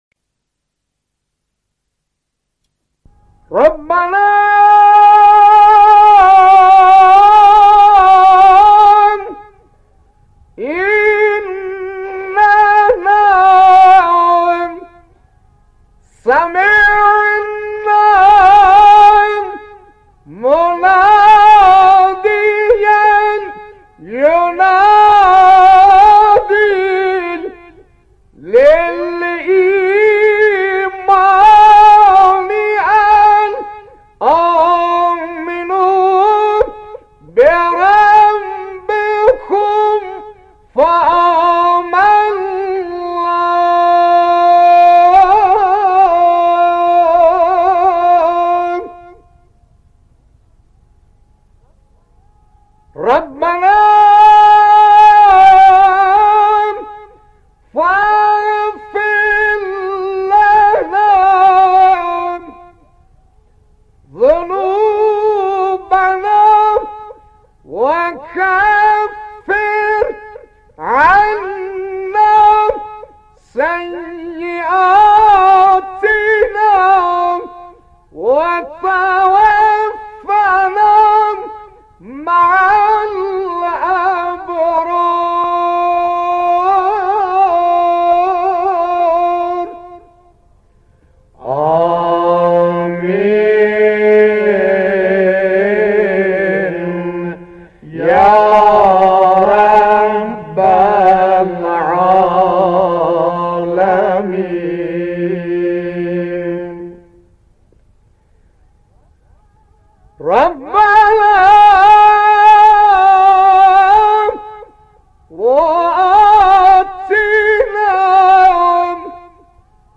نوای زیبای ربنا با صدای مرحوم حاج سلیم موذن زاده